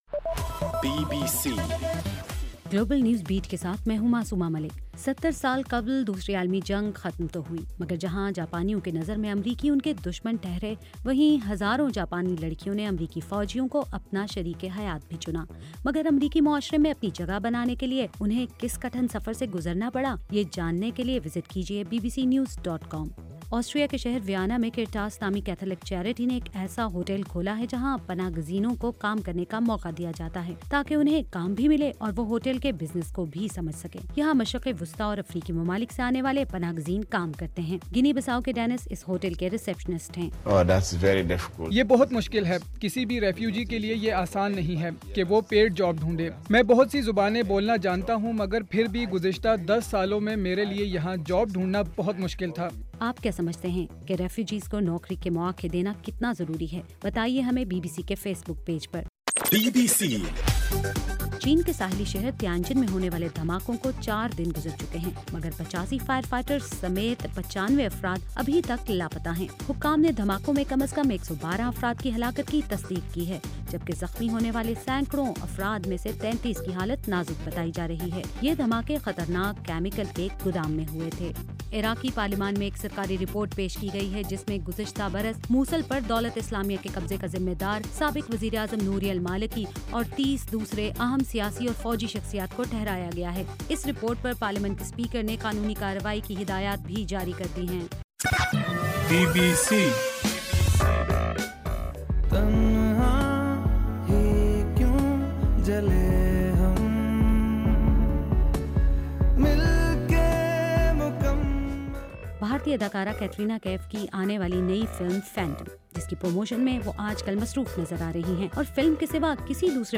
اگست 17 : صبح 1 بجے کا گلوبل نیوز بیٹ